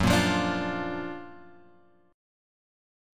F# Augmented Major 7th